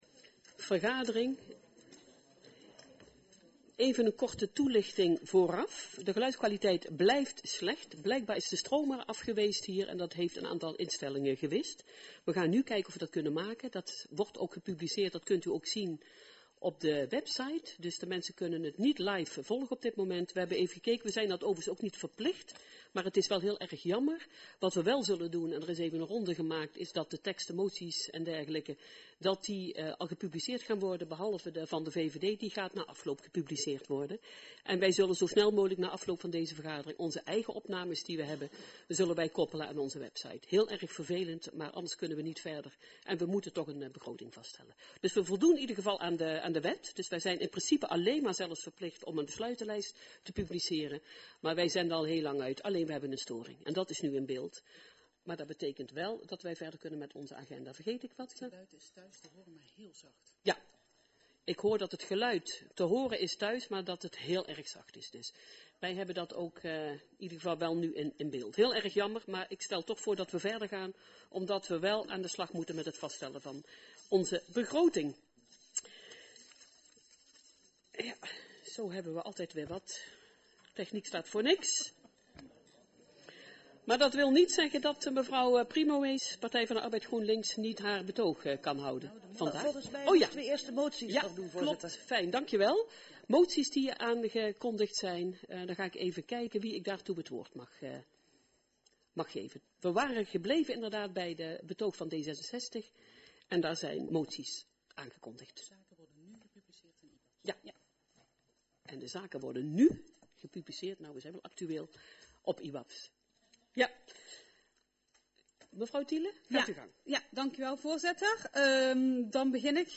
Agenda PeelenMaas - Raad Begroting 2023, aanvang 15.00 uur dinsdag 8 november 2022 15:00 - 22:24 - iBabs Publieksportaal
Omdat het geluid via de livestream slecht is, zijn de geluidsfragmenten apart als bijlagen toegevoegd.